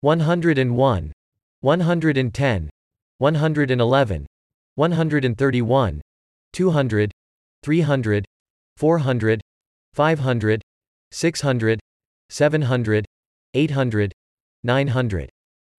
Prononciation 101-900 en anglais: